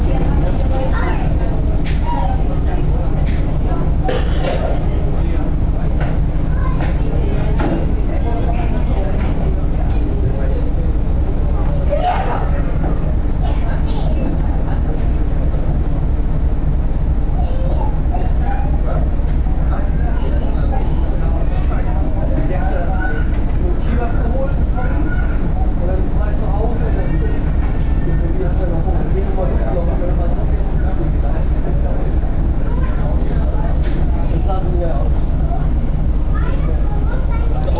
viele Gespräche gleichzeitig mit Nebengeräuschen
Auf einem Ausflugdampfer sitzen mehrere Personen und warten auf die Abfahrt des Schiffes.
Der Schiffsdiesel brummt vor sich hin. Gesprächsfetzen fliegen durch den Raum.
Abb. 02: Frequenzanalyse: Schiffsmotor und Gesprächsfetzen, was sagt das Kind?